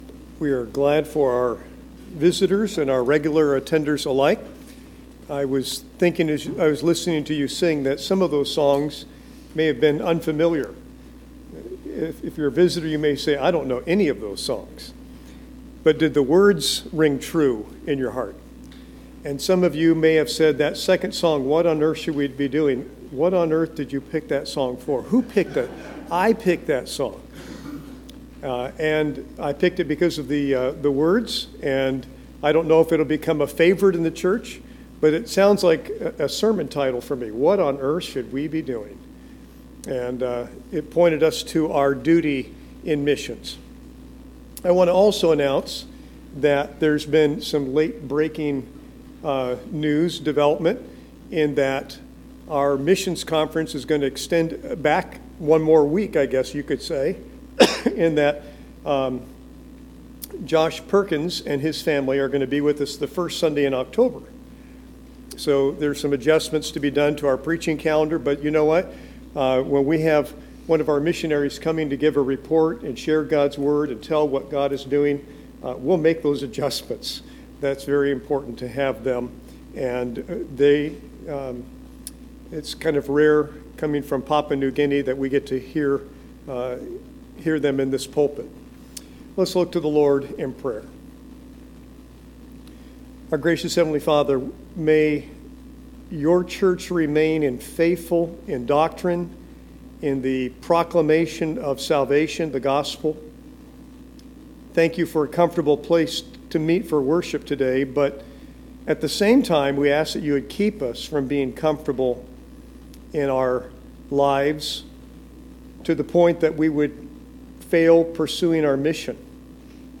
ServiceMissionary ConferenceSunday Morning